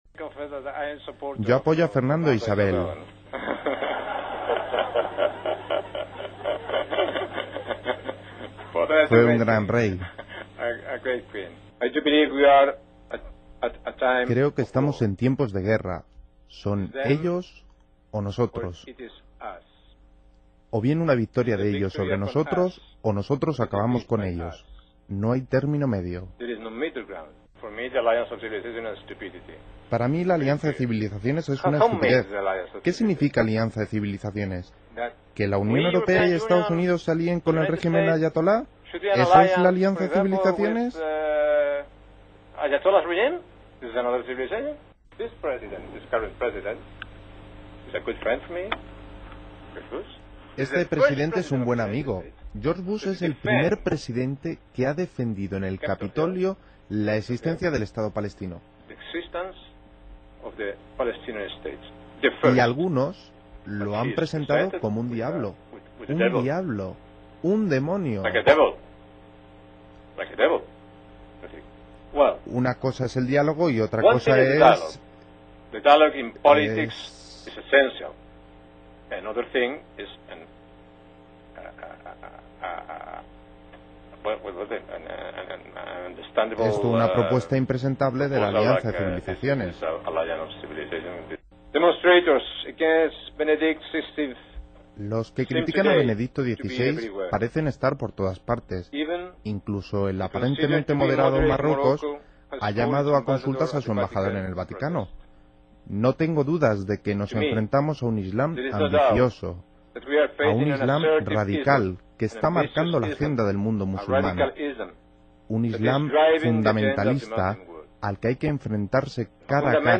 Ayer se descolg� en Estados Unidos, m�s concretamente en el nido neocon del Instituto Hudson con una conferencia sobre Amenazas Globales y Estructuras Atl�nticas en la que, con toda probabilidad, defendi� que Europa debe ser una colonia de los EEUU y que los tambores de guerra deber�an sonar tambi�n desde Tarifa a Noruega.
Lo peor de lo peor llega cuando en el turno de preguntas del p�blico asistente ya no hay gui�n que valga, ah� se tiene que enfrentar a pelo con su sapiencia al auditorio.